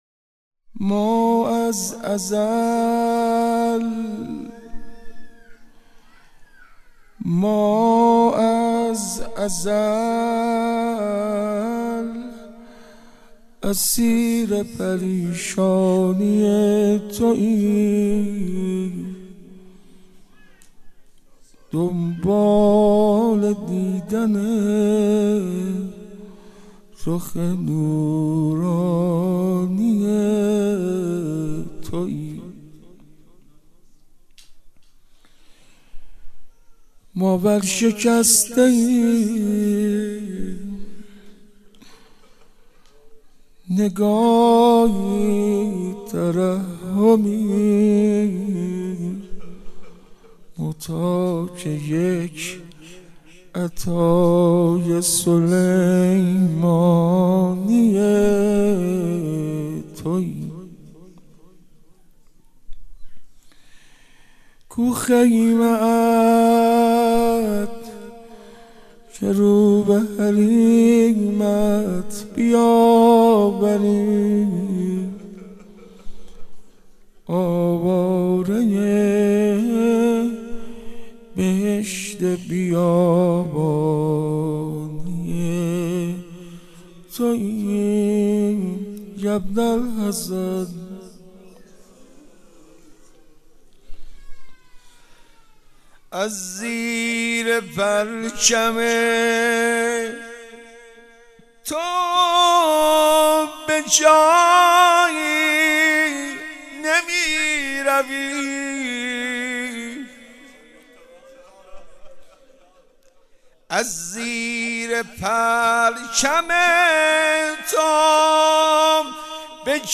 مناجات با امام زمان (عج)